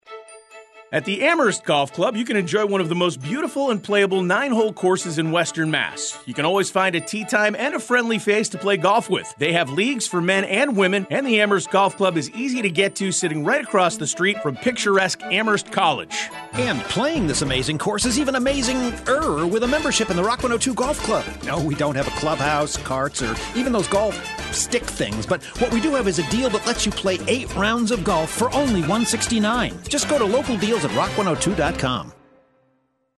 Rock 102 Golf Special Ad - Amherst Golf Club